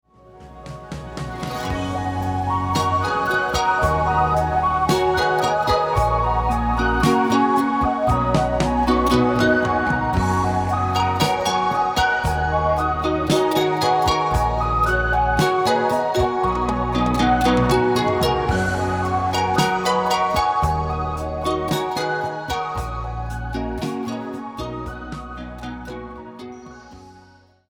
at the SINUS-Studio Bern (Switzerland)